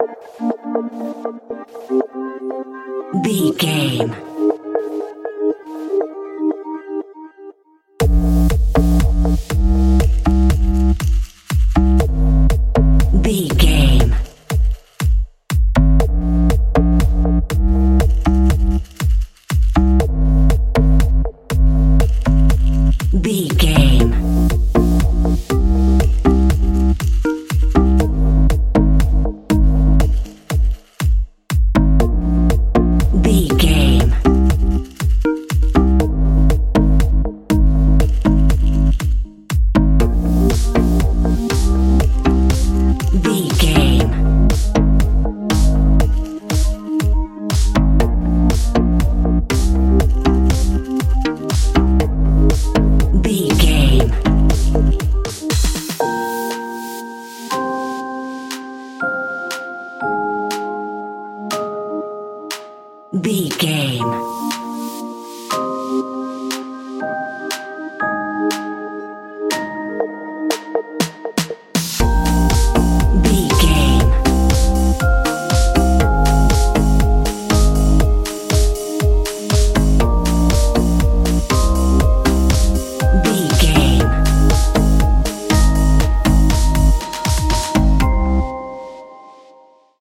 Uplifting
Aeolian/Minor
bouncy
synthesiser
drum machine
sleigh bells